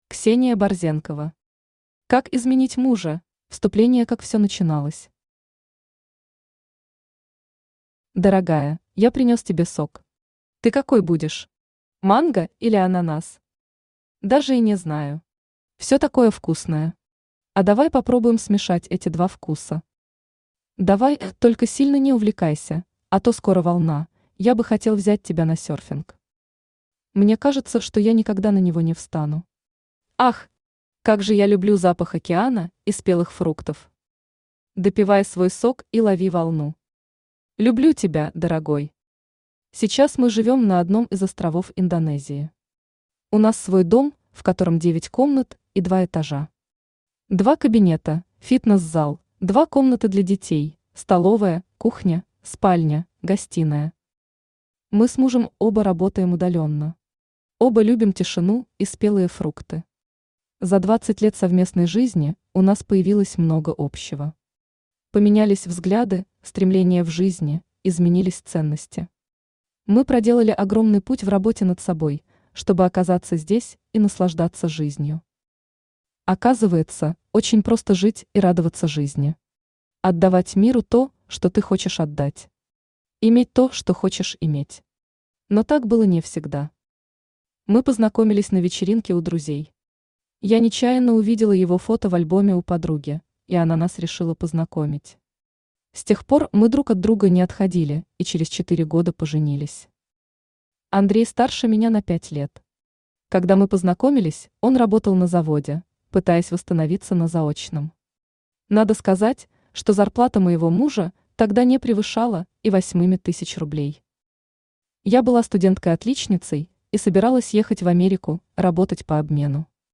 Аудиокнига Как изменить мужа?
Автор Ксения Николаевна Борзенкова Читает аудиокнигу Авточтец ЛитРес.